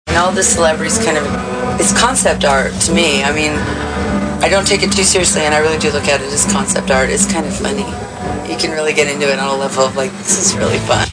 Interview on French TV, 1999